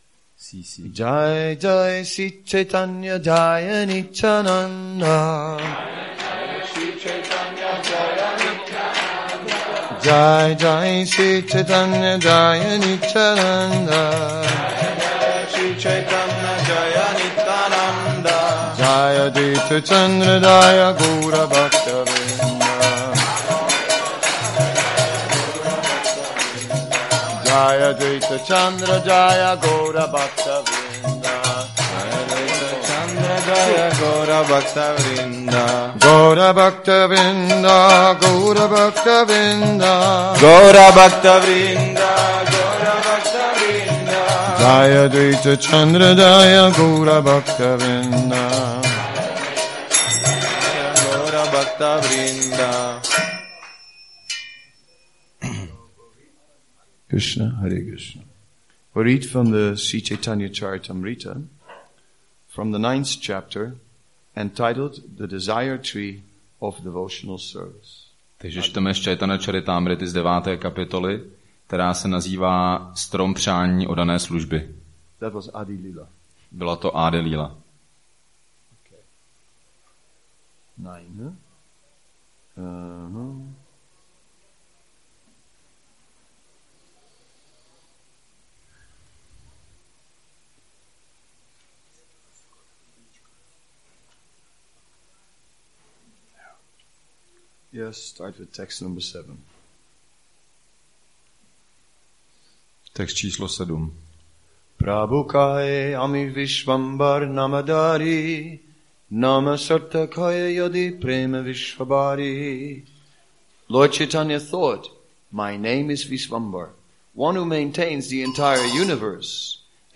Seminář 1 CC-ADI-9.7